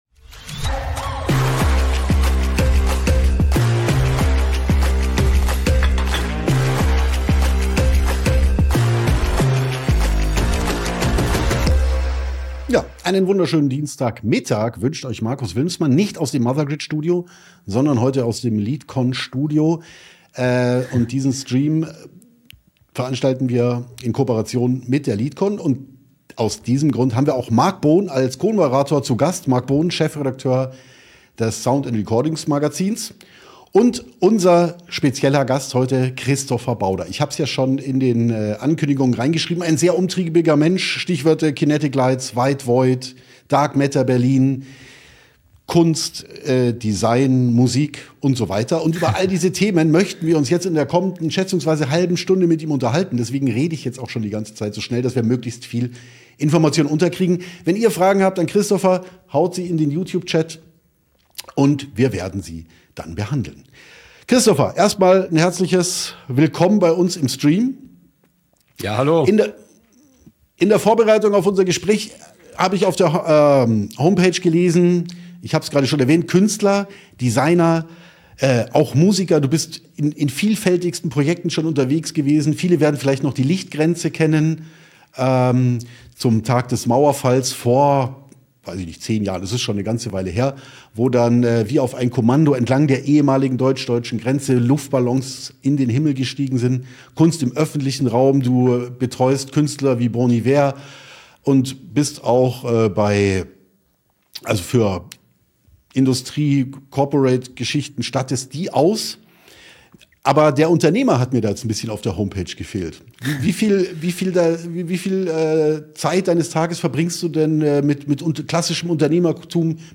Beschreibung vor 1 Jahr Wir freuen uns, euch zu einem besonderen Livestream mit dem renommierten Künstler und Designer Christopher Bauder einzuladen!